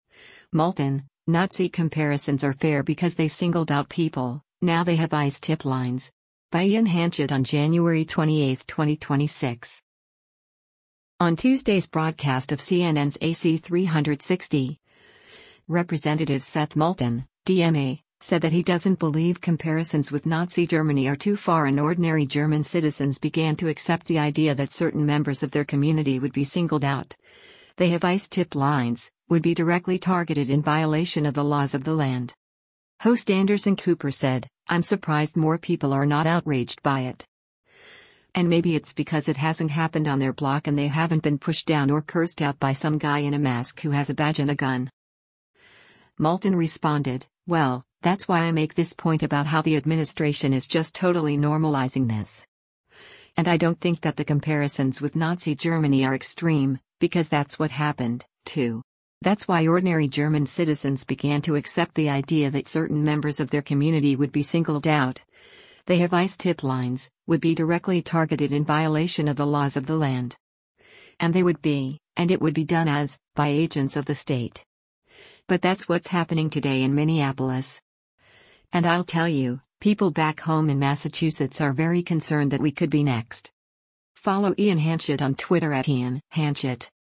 On Tuesday’s broadcast of CNN’s “AC360,” Rep. Seth Moulton (D-MA) said that he doesn’t believe comparisons with Nazi Germany are too far and “ordinary German citizens began to accept the idea that certain members of their community would be singled out, they have ICE tip lines, would be directly targeted in violation of the laws of the land.”